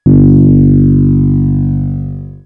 Hum33.wav